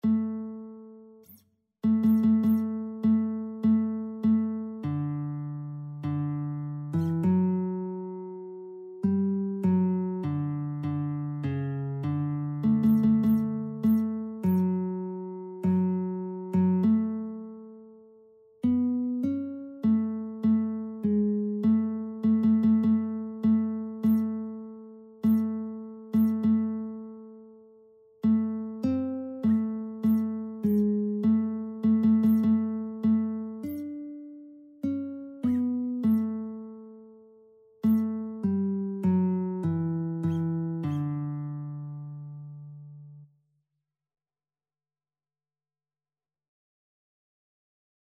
Christian Christian Lead Sheets Sheet Music God of the Ages, Whose Almighty Hand
D major (Sounding Pitch) (View more D major Music for Lead Sheets )
4/4 (View more 4/4 Music)
Classical (View more Classical Lead Sheets Music)